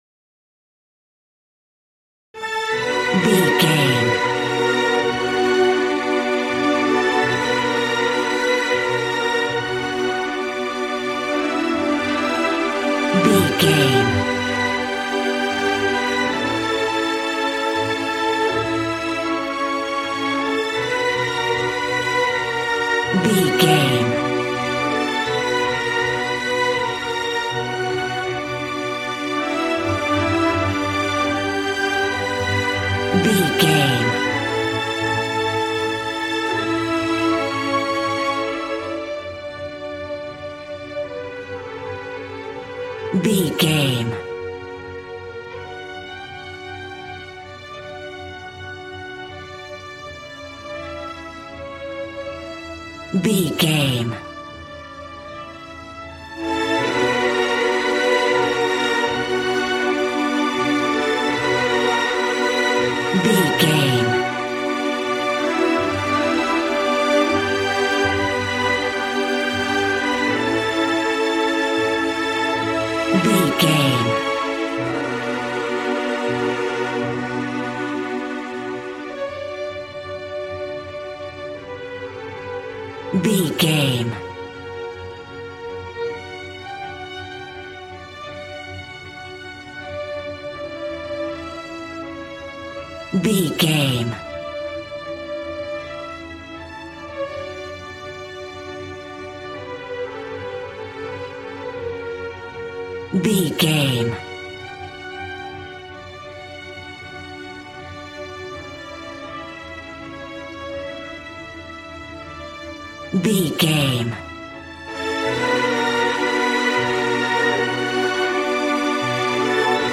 A classical music mood from the orchestra.
Regal and romantic, a classy piece of classical music.
Aeolian/Minor
regal
cello
violin
strings